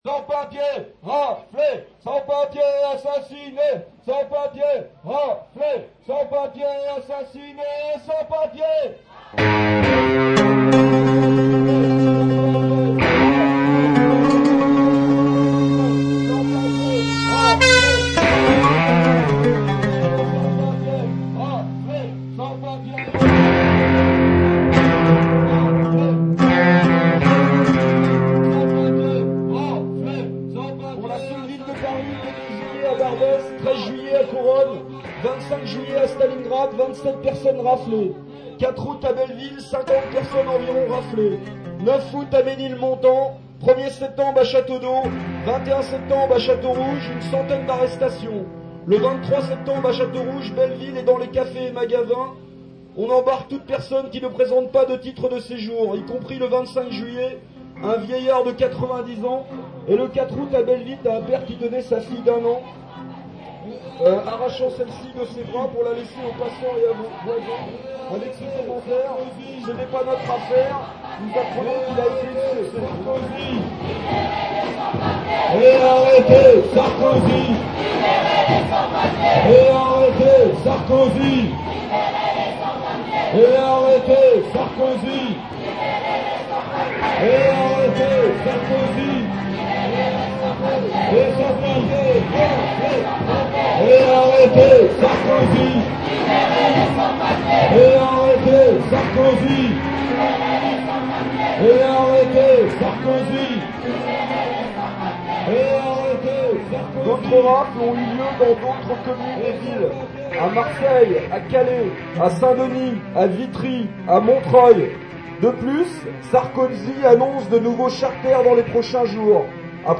avec les Sans-Papiers à Château Rouge.